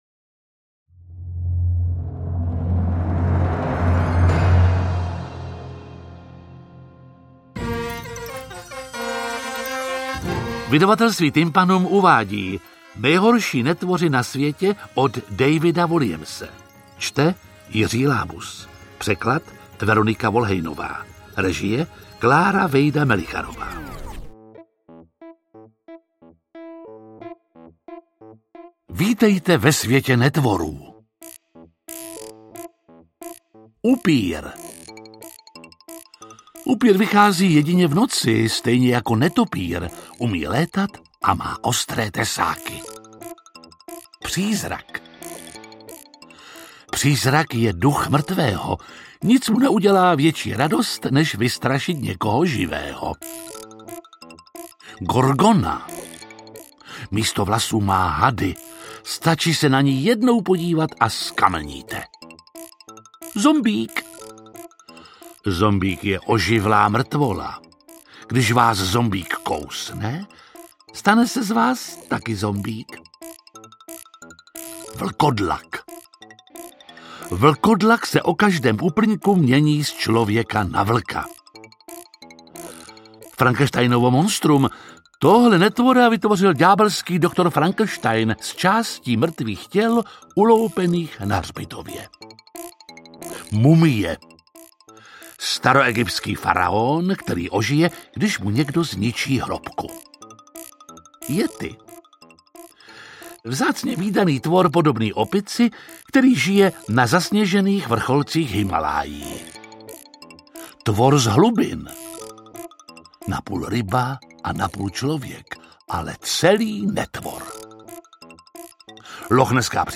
Interpret:  Jiří Lábus